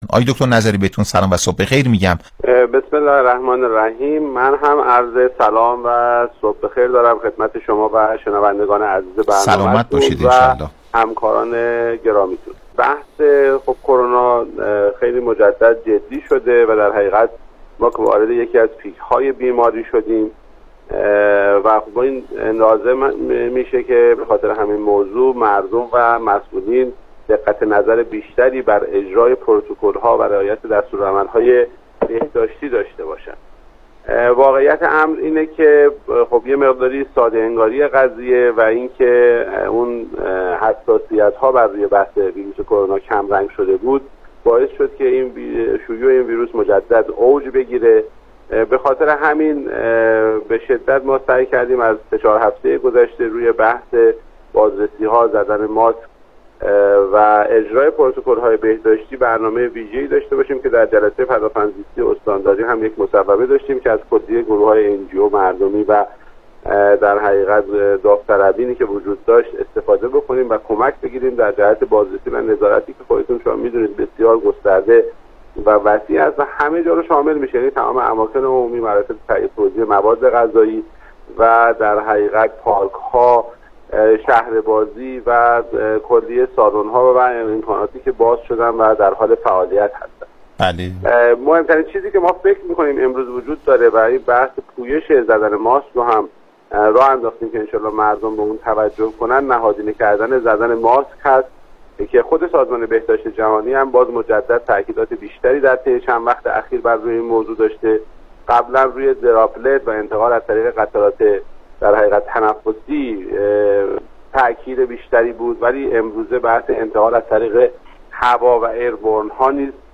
گفتگوی تلفنی برنامه پاسخ با دکتر نظری معاون بهداشتی و رئیس مرکز بهداشت استان مرکزی، مرداد ماه
برنامه رادیویی "پاسخ"